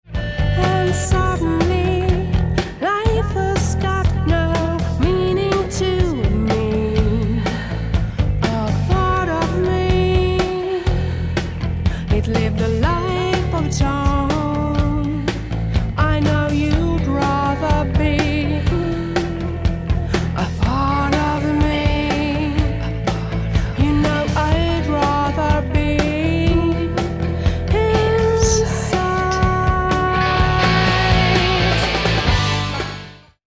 I say their music is Alt Pop Rock